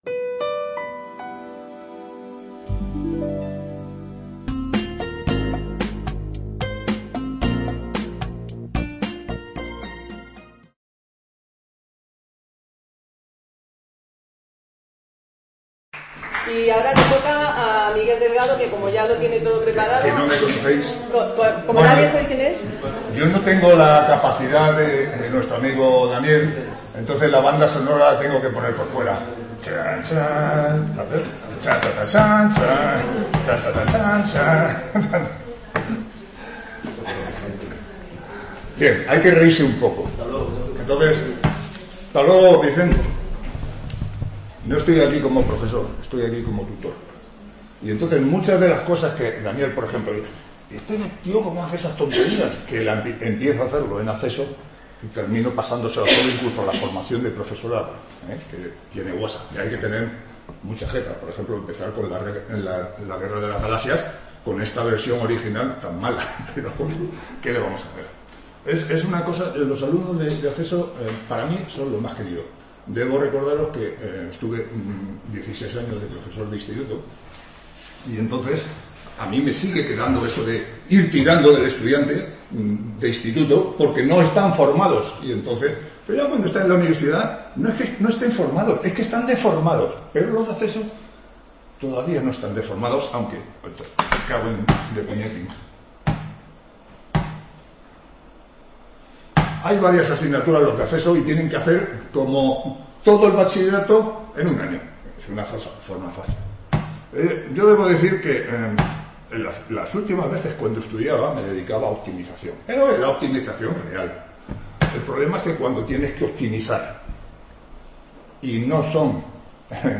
Ponencia
Estas jornadas se desarrollan en el Centro Asociado de la UNED de Úbeda y pretenden ser un punto de encuentro para compartir experiencias educativas reales en Estadística y/o Matemáticas que se enmarquen en el ámbito cotidiano de la docencia en los diferentes niveles de la educación Matemática, aunque está abierto a docentes de otras materias.